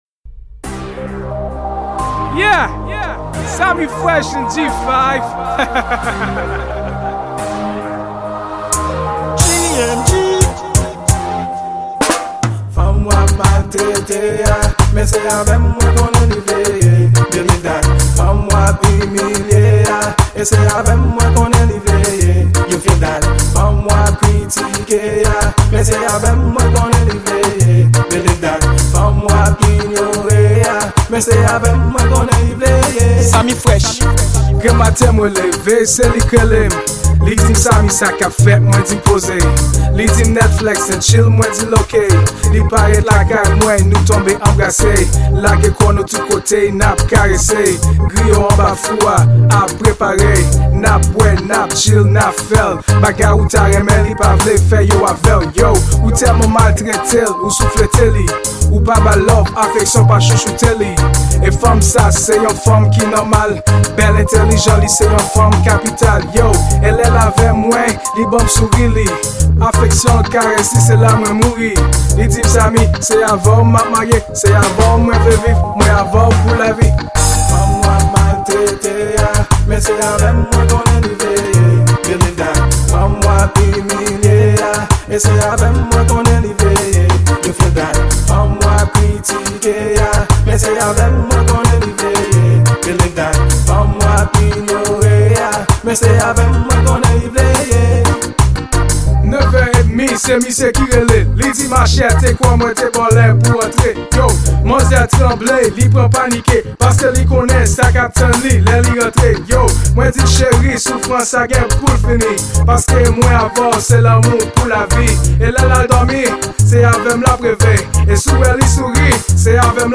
Genre: Reggae.